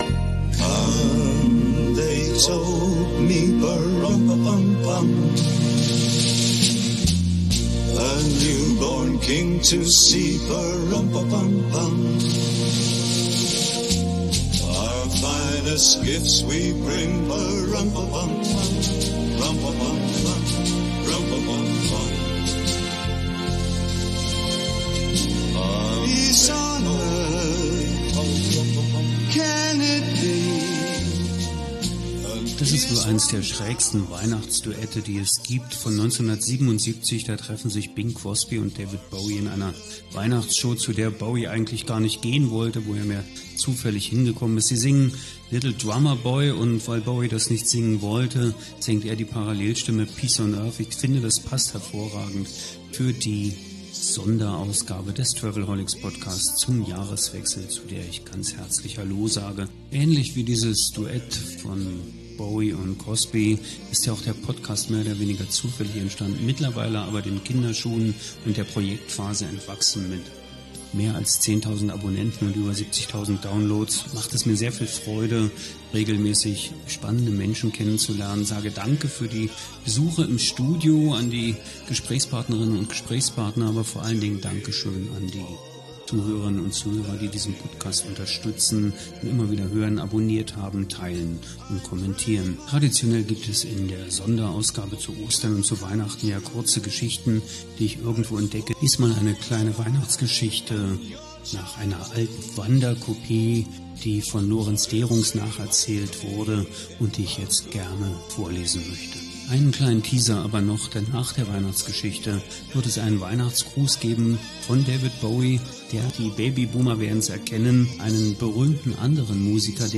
Mit diesen Worten aus dem "Little Drummer Boy" wird die letzte Podcastepisode des Jahres 2022 eingeleitet, ein besonderes Duett aus dem Jahr 1977 gibt den Ton dazu.